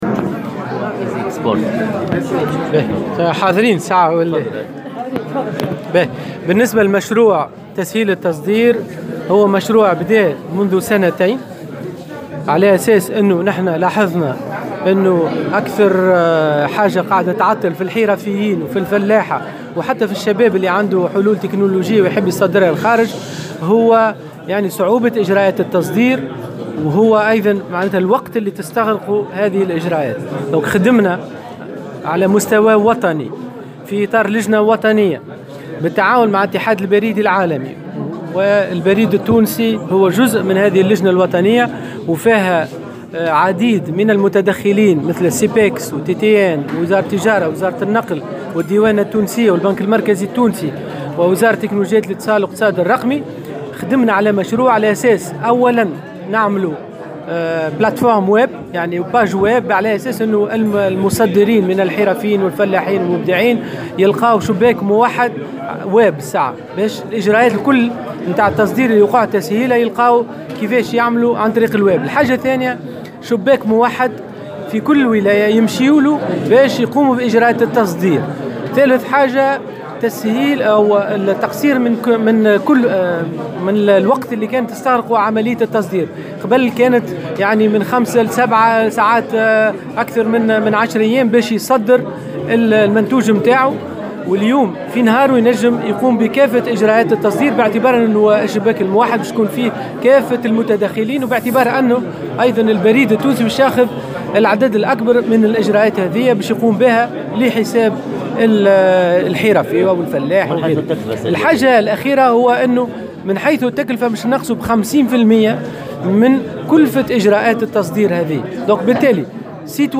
قال وزير تكنولوجيا الاتصال و الاقتصاد الرقمي أنور معروف اليوم الإثنين في تصريح لمراسل الجوهرة "اف ام" بالقيروان على هامش اشرافه على اطلاق مشروع " Easy Export " إن العمل على هذا المشروع بدأ منذ سنتين .